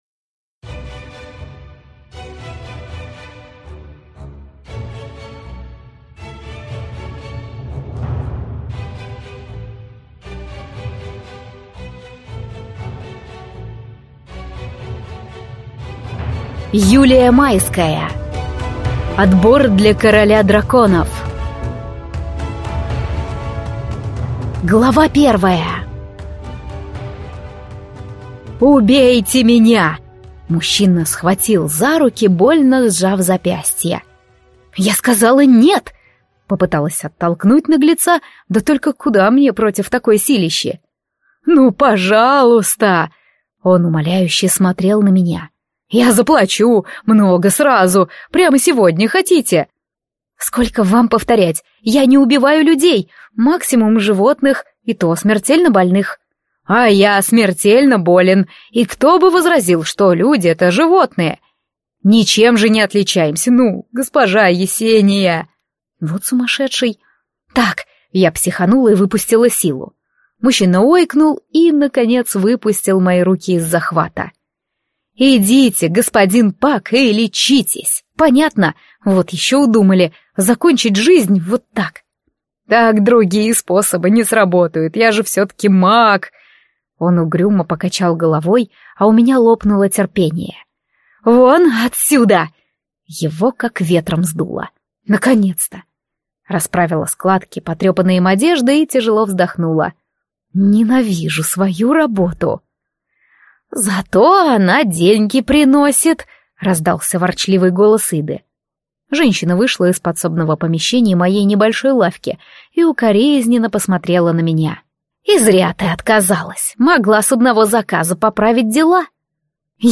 Аудиокнига Отбор для короля драконов | Библиотека аудиокниг